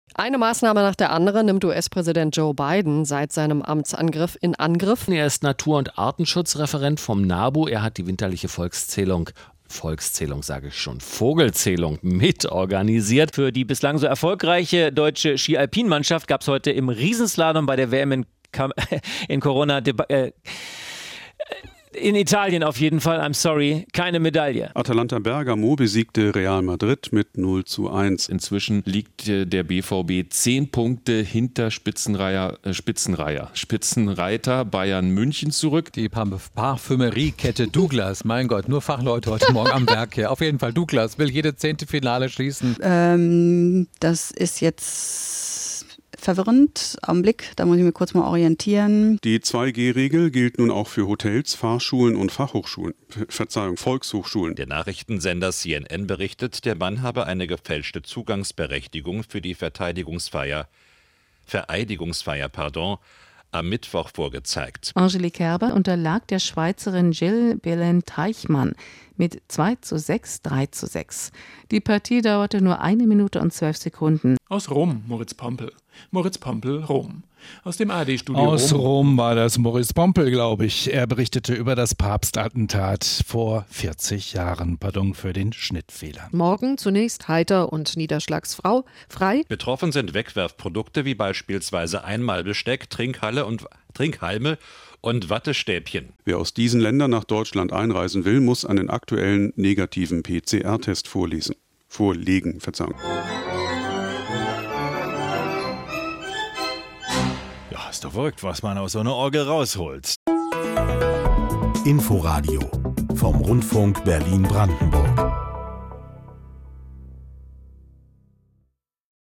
Auch 2021 war die Inforadio-Redaktion mit vollem Elan am Mikfrofon im Einsatz. Dabei läuft nicht immer alles wie geplant. Da geht es schon mal um die Ski-WM in Corona, winterliche Volkszählungen oder betroffene Trinkhallen. Das Best-of der schönsten Versprecher 2021.